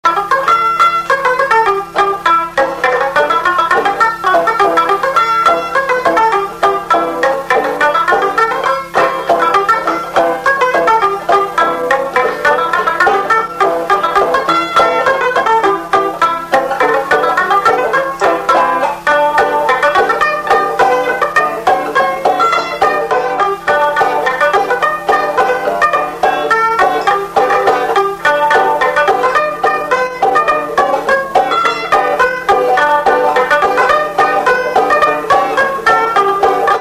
Polka
Saint-Leu
Instrumental
danse : polka